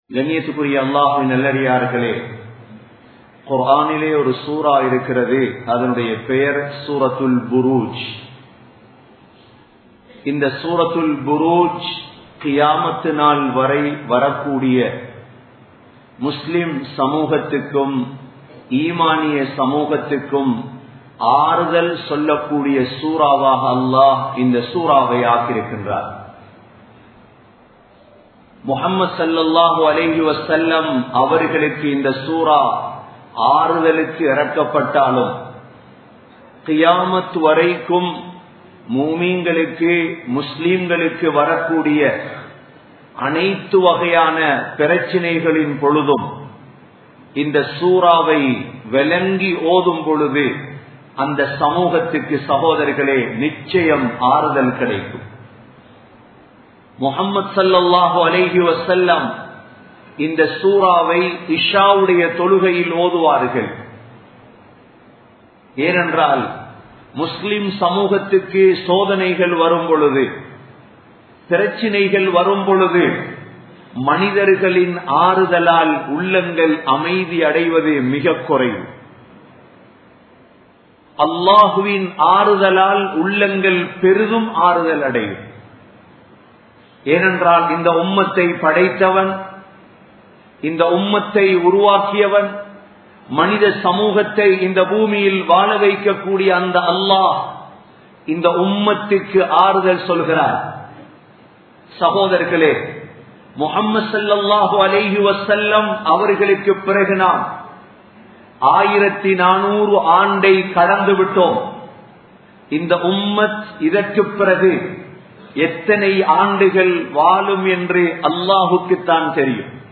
Muslimkalukku Allah Koorum Aaruthal (முஸ்லிம்களுக்கு அல்லாஹ் கூறும் ஆறுதல்) | Audio Bayans | All Ceylon Muslim Youth Community | Addalaichenai
Colombo 11, Samman Kottu Jumua Masjith (Red Masjith)